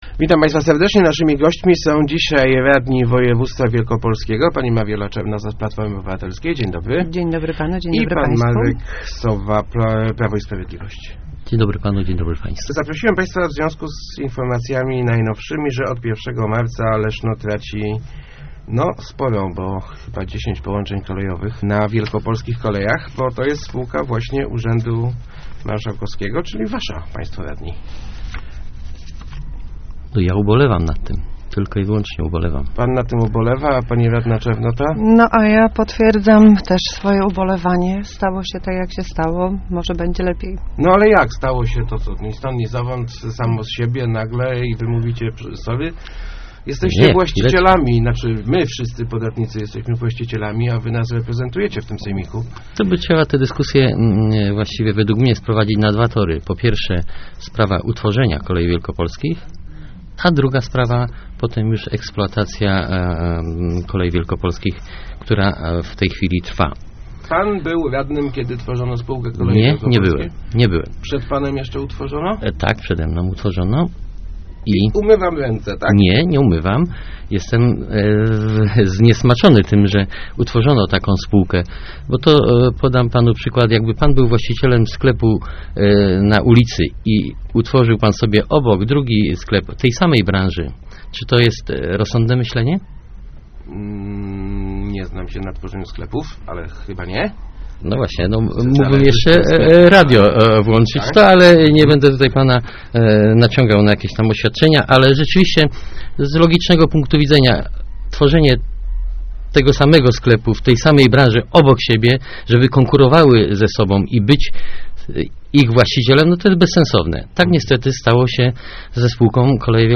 Ubolewam nad tym, ale taka jest ekonomia - mówiła w Rozmowach Elki radna Sejmiku Mariola Czarnota z PO, komentując likwidację połączeń Leszna z Krotoszynem, Gostyniem i Wolsztynem przez Koleje Wielkopolskie.